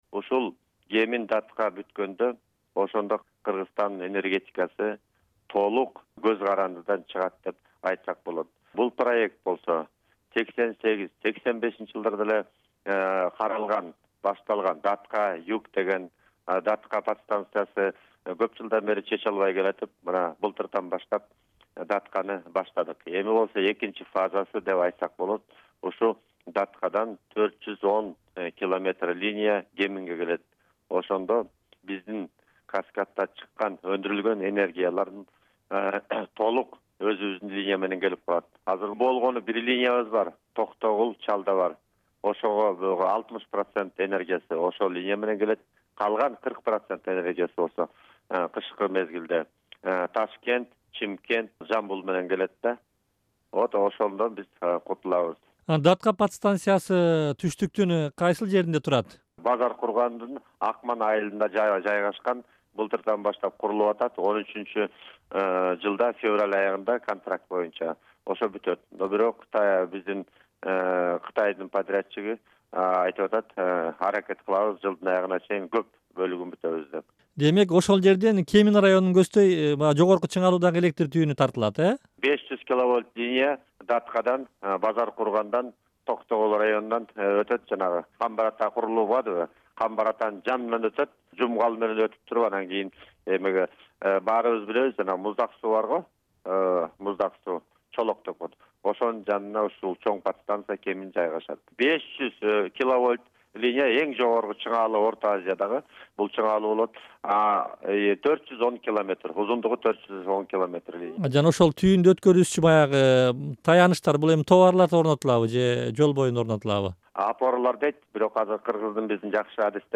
Президент Алмазбек Атамбаевдин Кытайдагы сапары учурунда түштүктөгү “Датка” подстанциясынан Кеминди көздөй жогорку чыңалуудагы электр түйүнүн курууну караган келишимге кол коюлду. Энергетика министринин орун басары Автандил Калмамбетовдун маеги